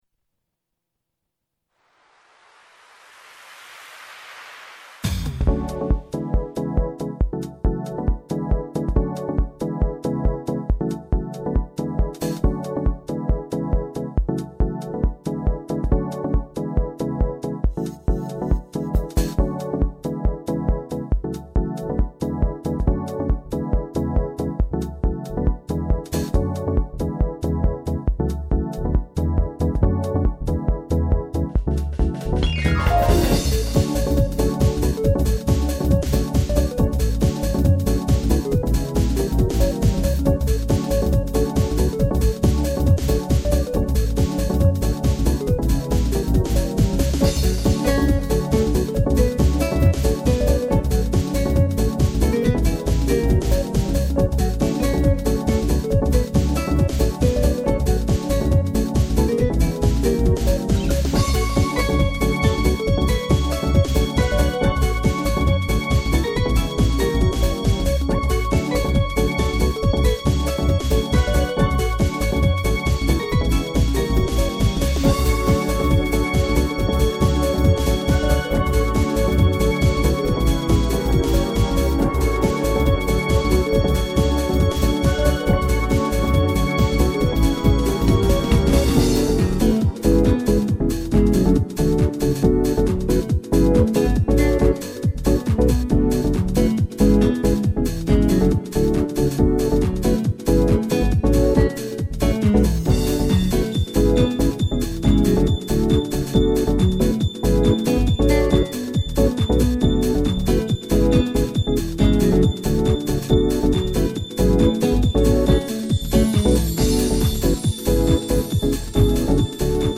spd garage
涼しげな夏の浜辺のイメージの曲。